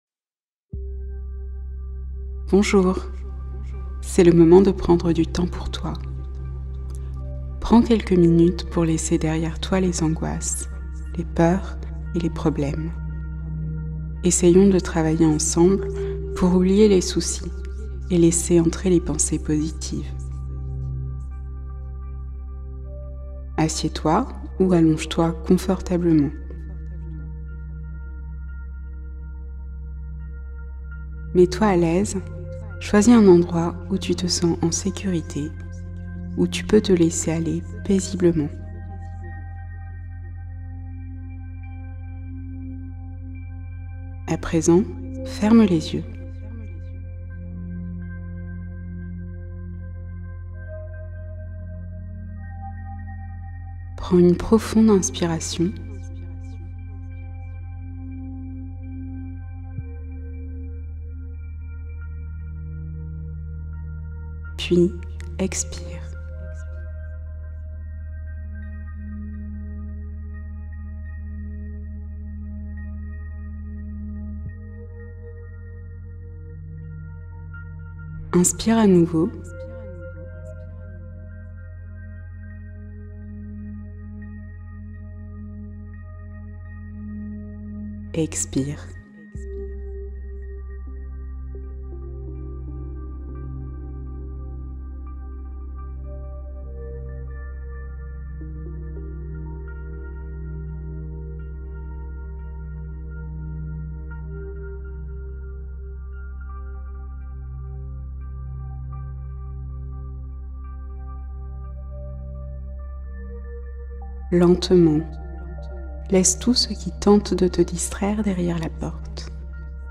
Harmonisation des 7 chakras : séance guidée d’équilibre énergétique